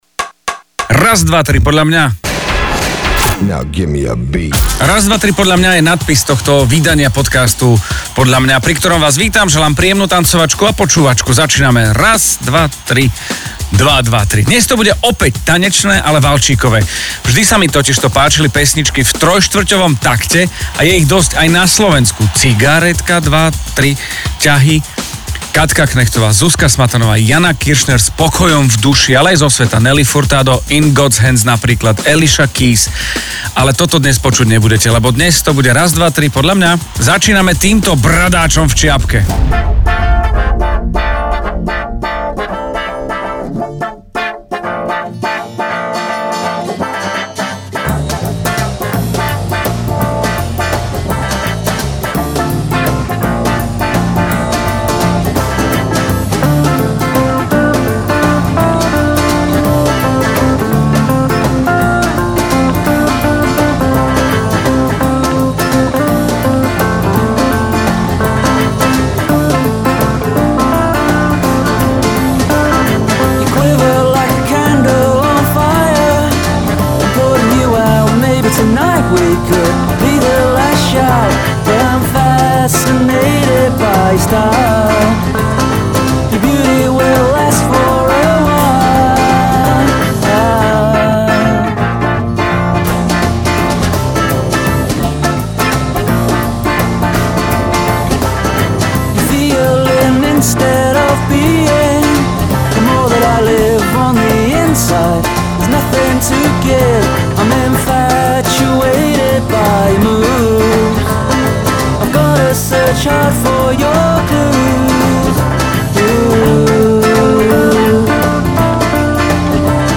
Valčíková tancovačka
hudobný podcast